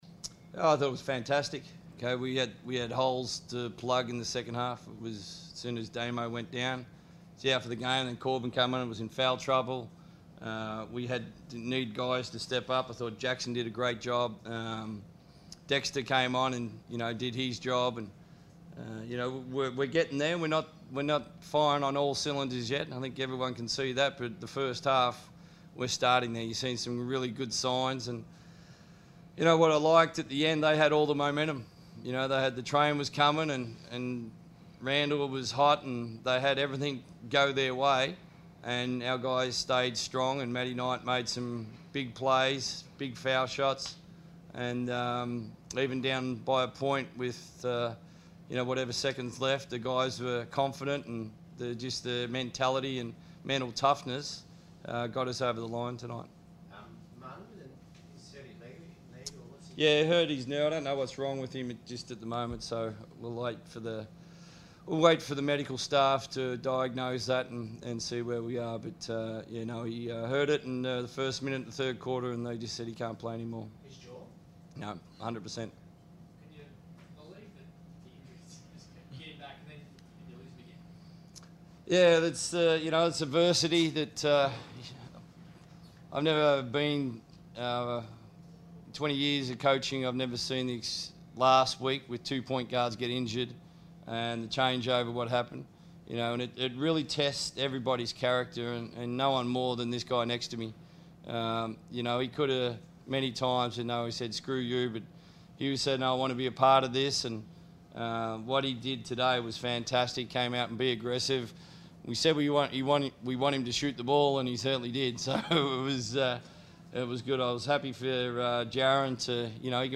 speak to the media following the Perth Wildcats thrilling win over the Adelaide 36ers.